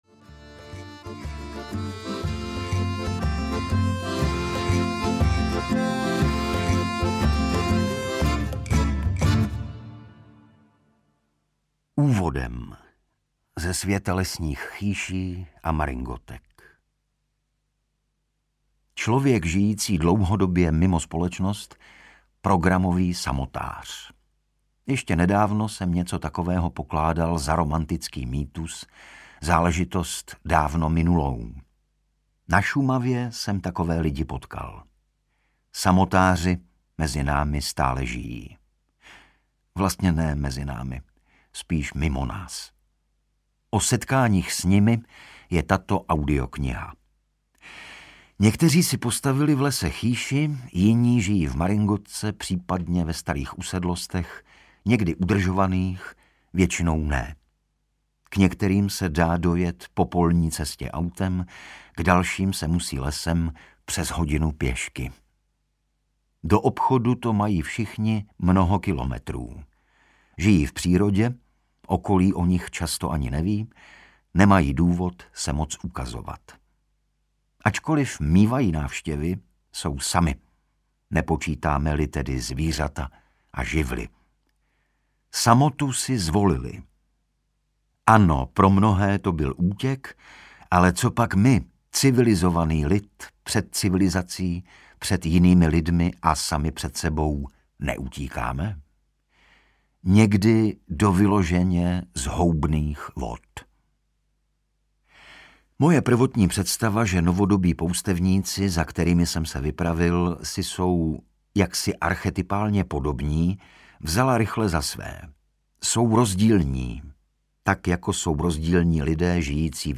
Raději zešílet v divočině audiokniha
Ukázka z knihy
Osm předních hereckých osobností přináší příběhy poustevníků duše, kteří dali vale naší „civilizaci“.Někteří samotáři stojí nohama pevně na zemi, jiní vyprávějí svůj život trochu jako mýtus.
• InterpretIgor Bareš, Norbert Lichý, Jana Stryková, David Novotný, Petr Čtvrtníček, Leoš Noha, Vilma Cibulková, Stanislav Šárský
radeji-zesilet-v-divocine-audiokniha